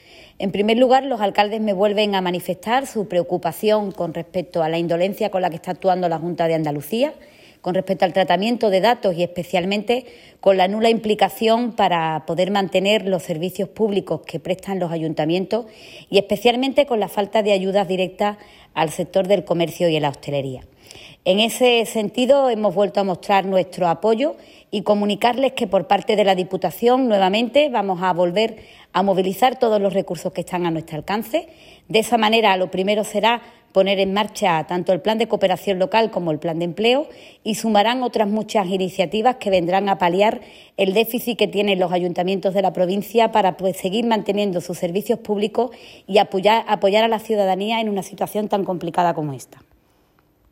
Audio de Irene García tras reunirse con los alcaldes de La Janda